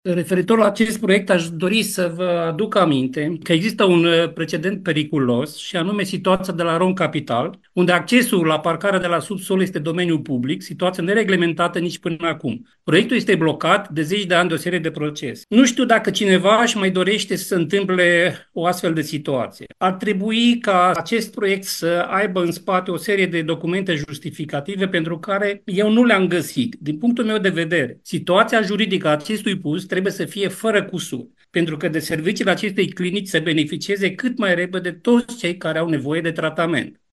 Înainte de votul din plen, conslierul local AUR Ilie Sârbu a invocat mai multe nereguli juridice.